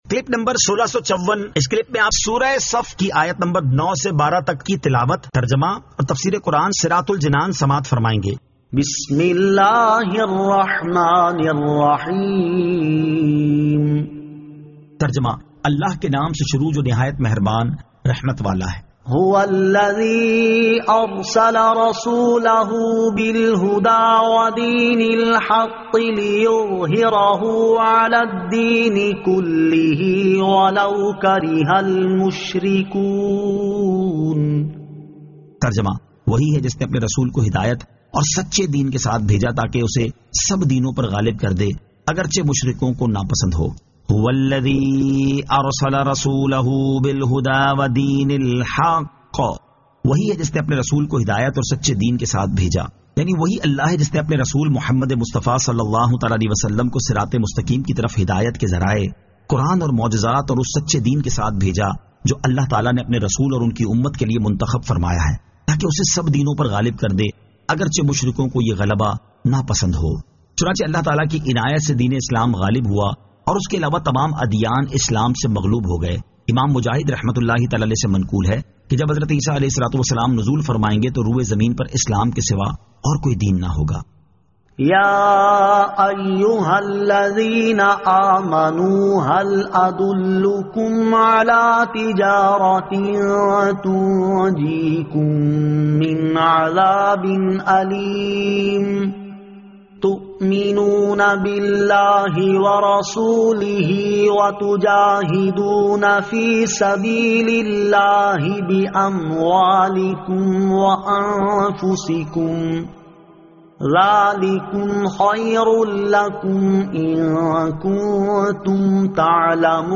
Surah As-Saf 09 To 12 Tilawat , Tarjama , Tafseer